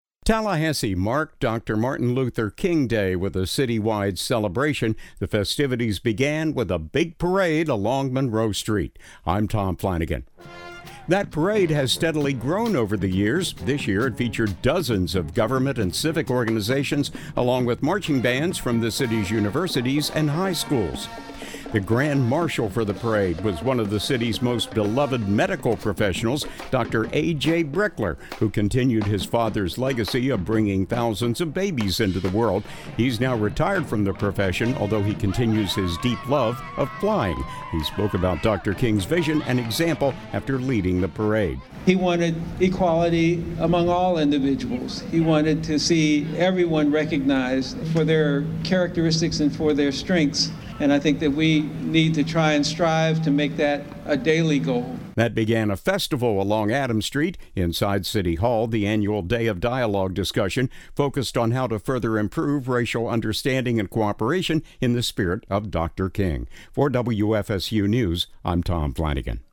Tallahassee marked Dr. Martin Luther King Day with a city-wide celebration. The festivities began with a big parade along Monroe Street.